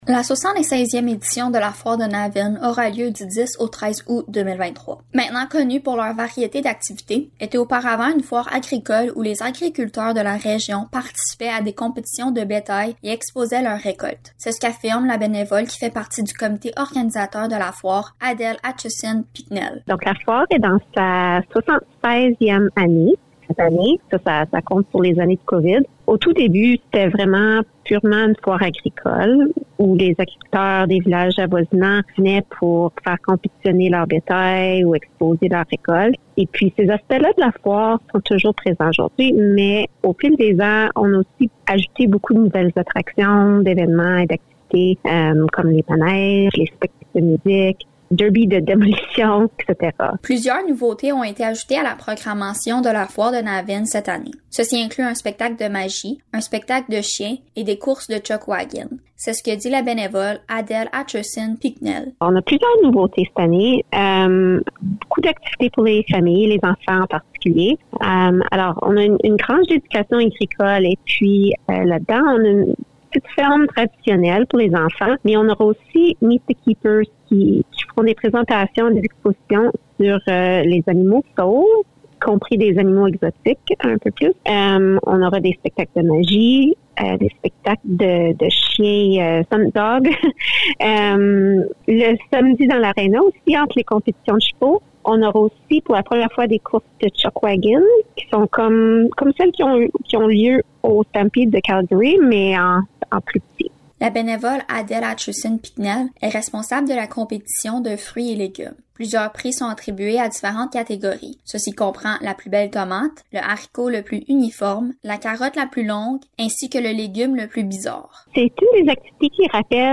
Reportage-Foire-de-Navan-20-juillet.mp3